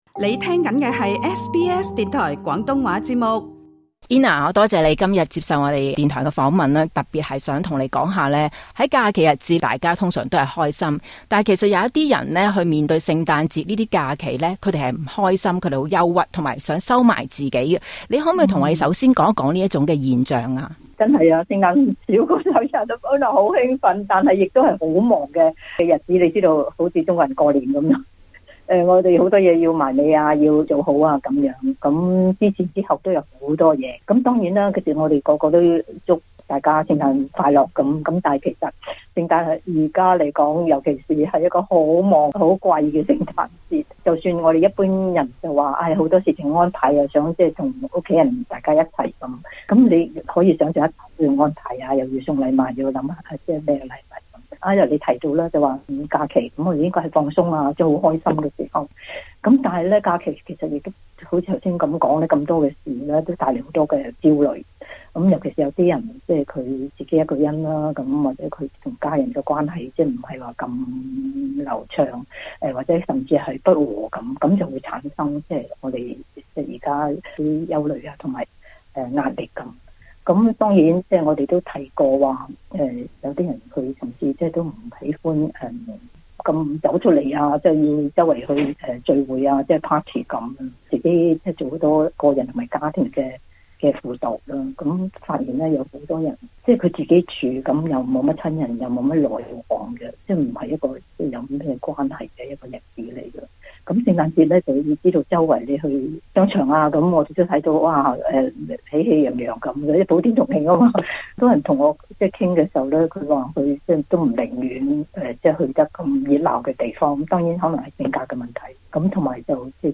時事專訪